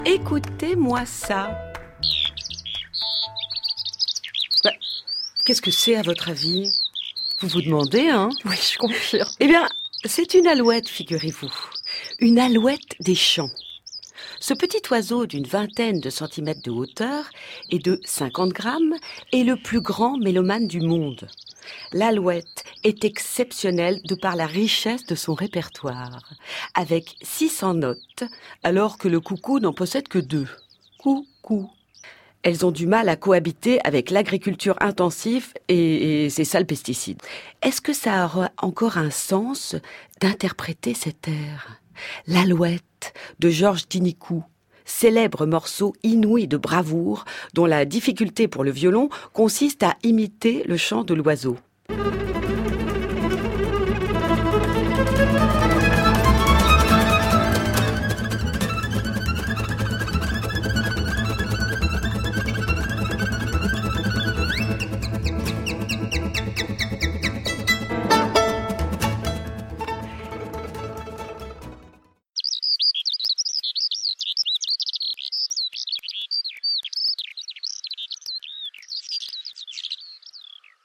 écouter) est l’oiseau qui possède le répertoire le plus riche au monde avec plus de 600 notes articulées en phrases.
alouette.mp3